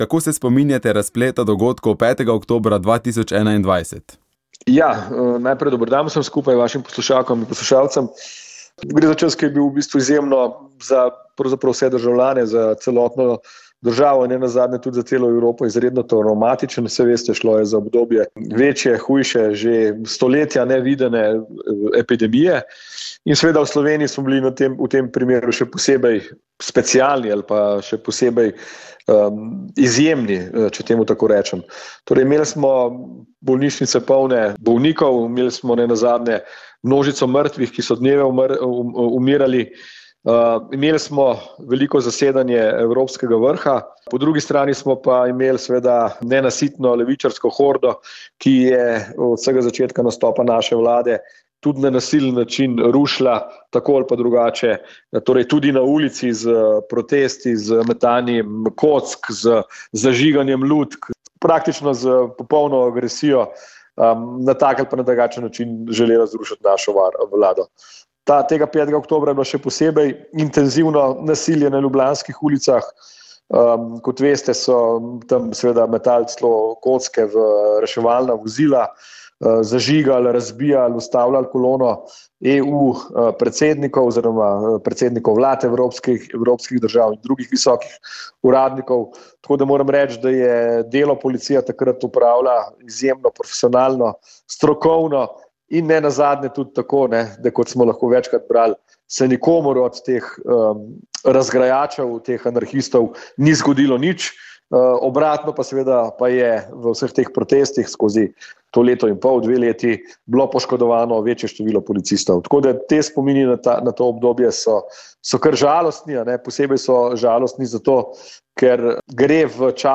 Ob Svetovnem dnevu Zemlje, ki ga obeležujemo dvaindvajsetega aprila, smo k pogovoru za poljudnejšo razlago podnebnih sprememb povabili vse bolj prepoznavnega in priznanega meteorologa, ki pri svojem profesionalnem delu združuje med drugimi tudi znanja fizike, matematike, tudi kemije, v prepletu z meteorologijo.